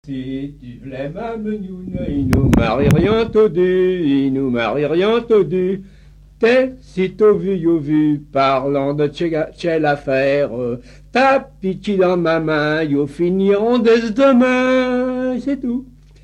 Mémoires et Patrimoines vivants - RaddO est une base de données d'archives iconographiques et sonores.
Rondes à baisers et à mariages fictifs
Pièce musicale inédite